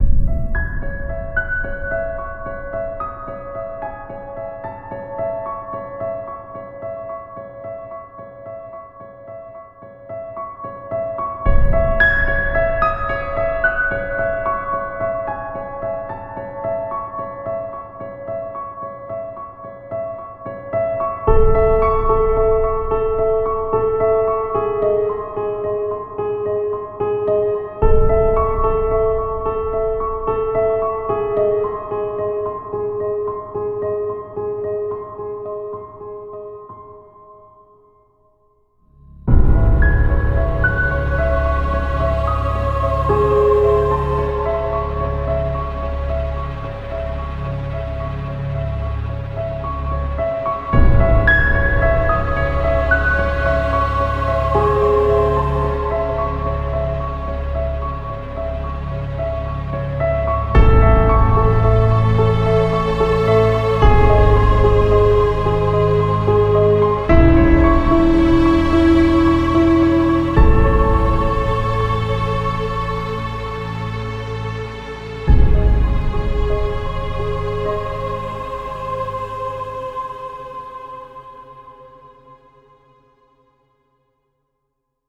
Horror\thriller music.